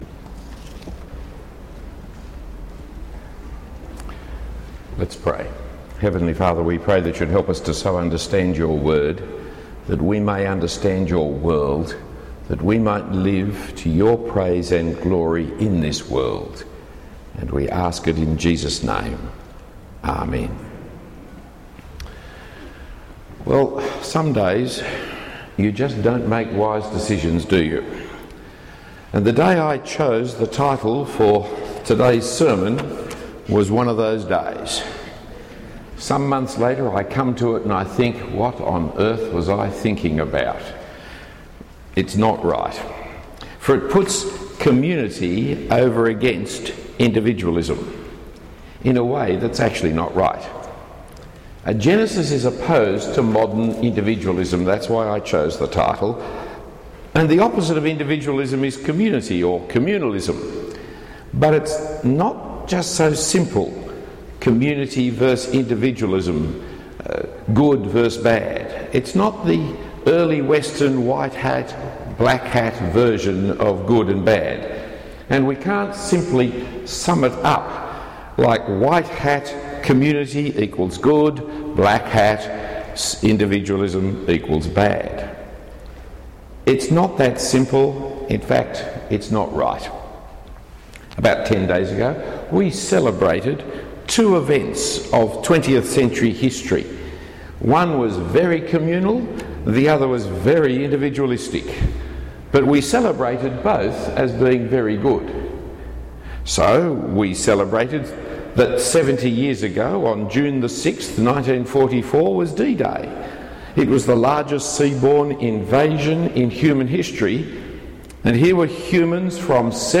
Talk 5 of 16 in the series: The Bible’s Challenge to a Dead World (Genesis 1-11)